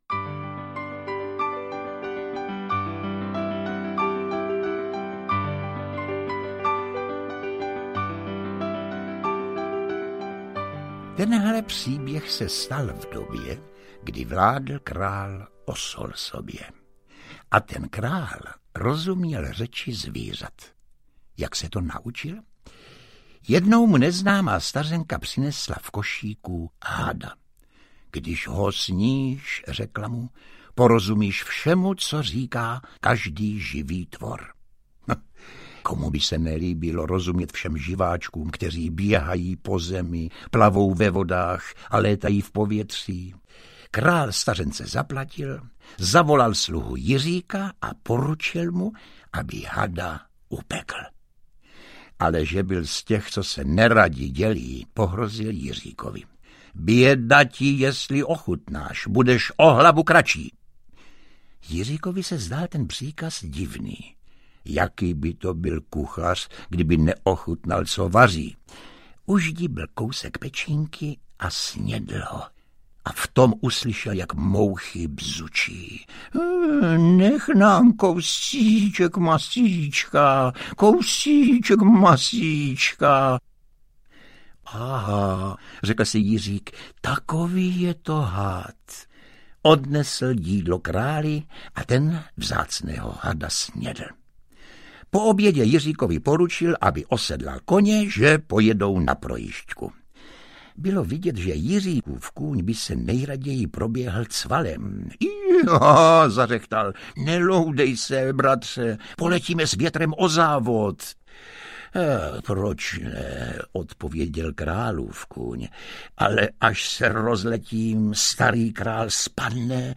Ukázka z knihy
• InterpretJosef Somr